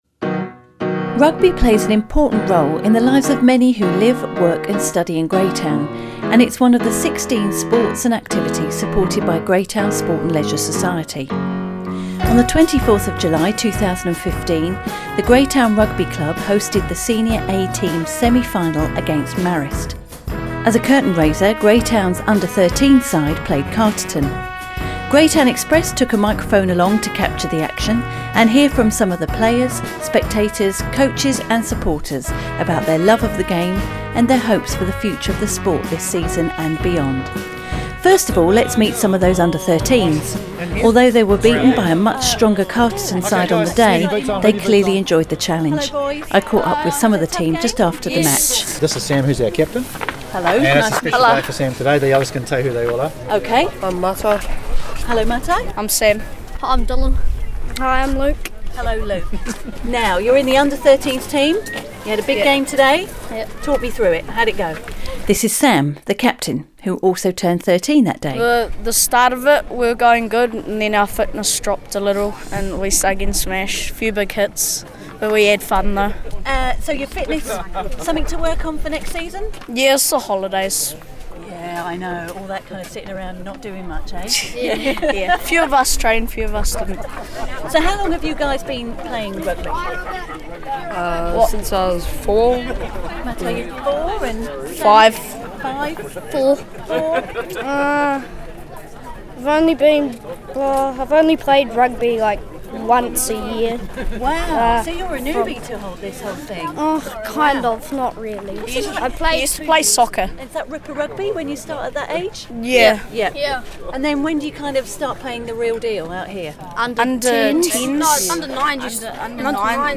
I’ve been out and about a bit with my mobile recorder snaffling lots of lovely audio and then wrangling it into these two pieces.
The second is a glimpse into the goings on at the Greytown Rugby Club, one of the sport organizations supported by Greytown Sport and Leisure Society. This feature was recorded on the day Greytown Senior A’s encountered the Masterton based Marists to determine who would progress to the Tui Cup 2015 final!